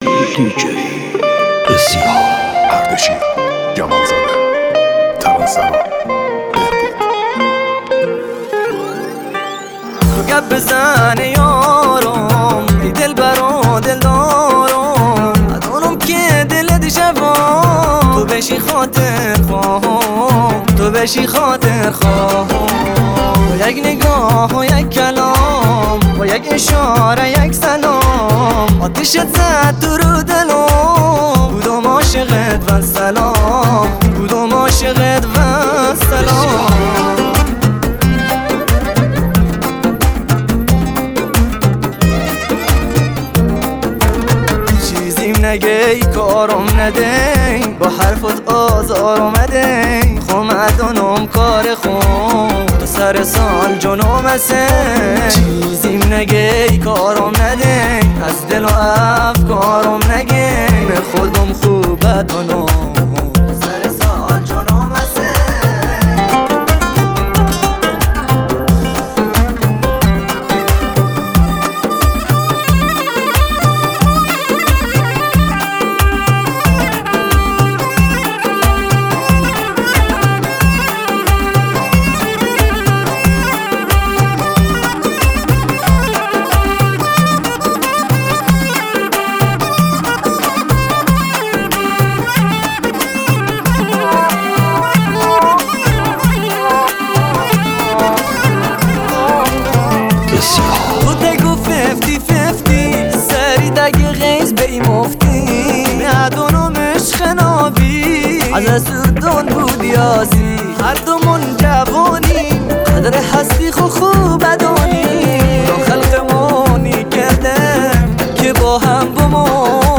دانلود آهنگ بستکی